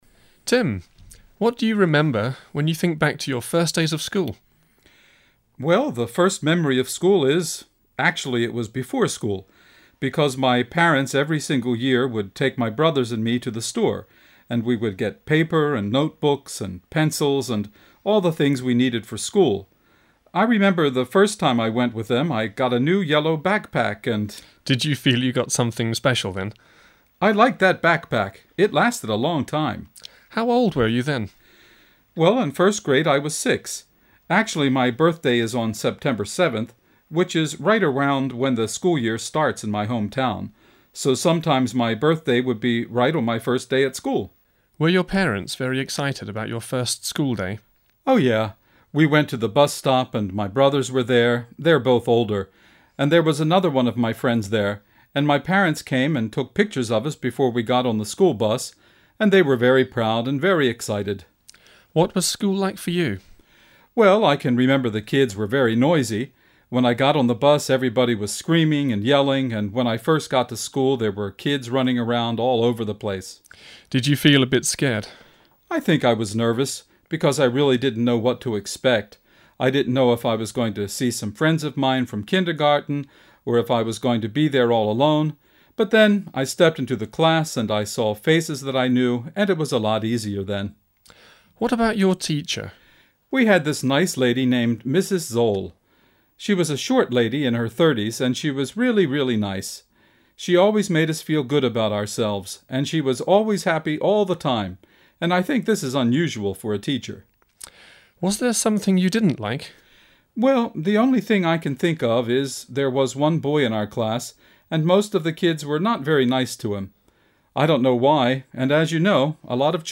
Memories of school - interview with an American student